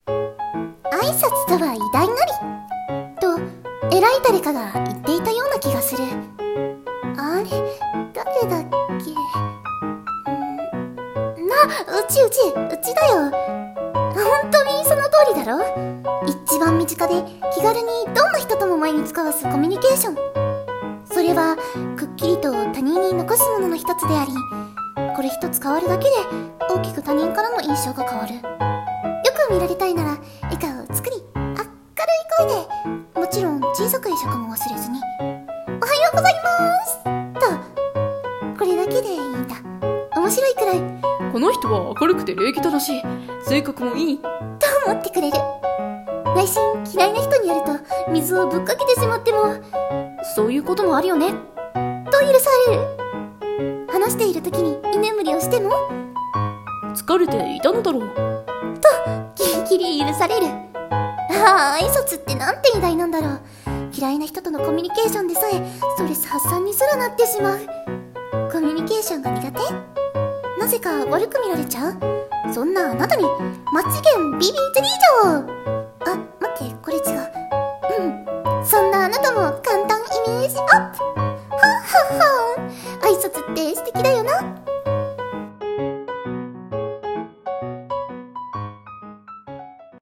コメディ風【一人声劇】あいさつ